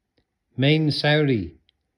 8. мэйнсаури